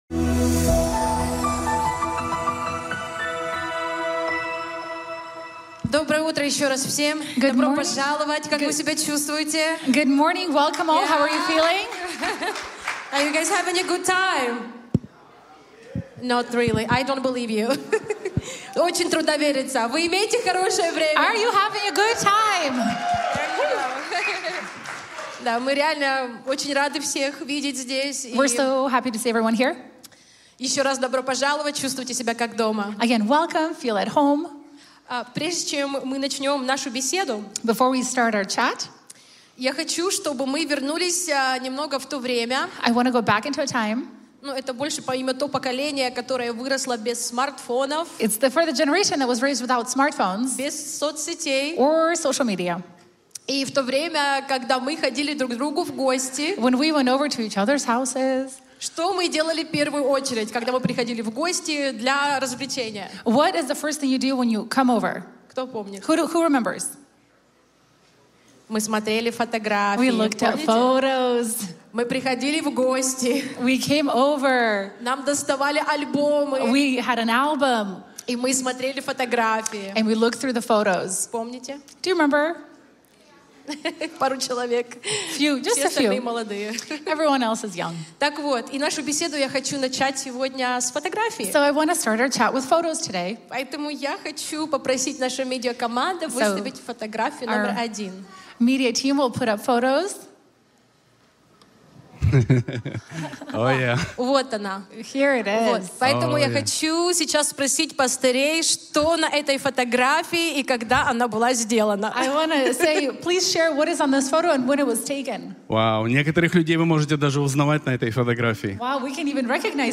Категория : Проповеди пастора
20_Year_Church_Anniversary.mp3